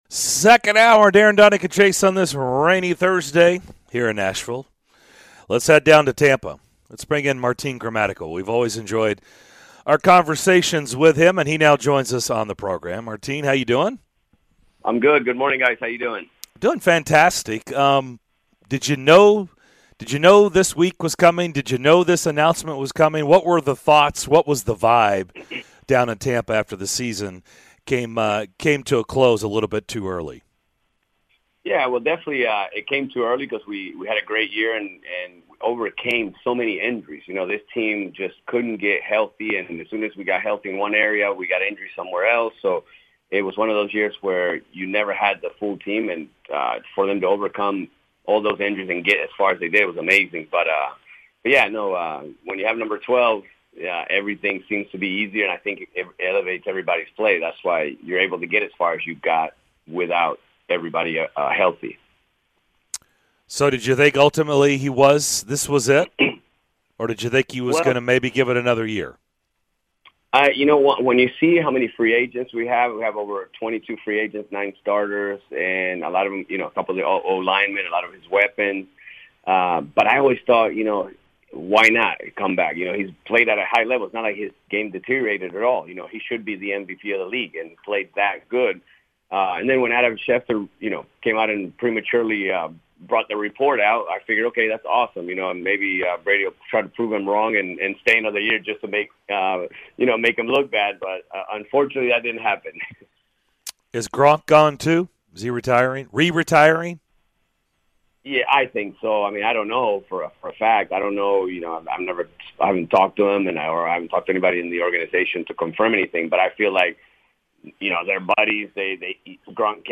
Former Buccaneers Kicker Martin Gramatica joined the show to discuss Tom Brady's retirement, the guys react to Jimbo Fisher's funny rant and more in hour number two of Thursday's show!